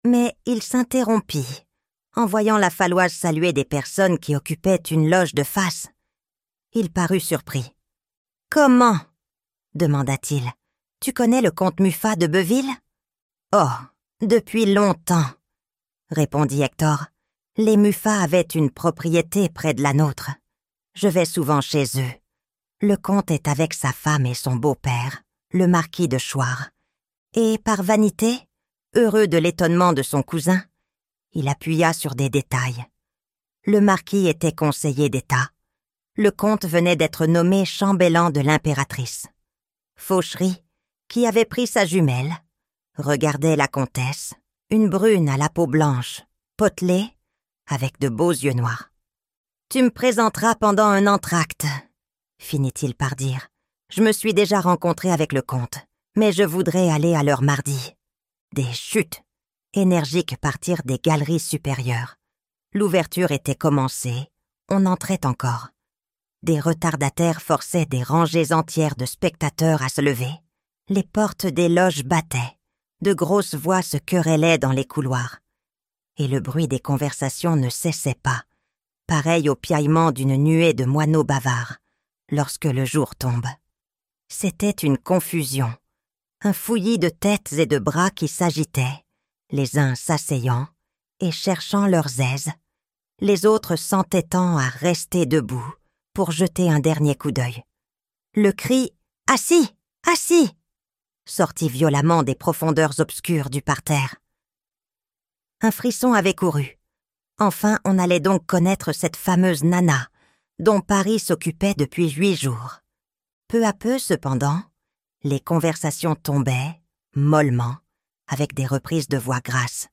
Nana - Livre Audio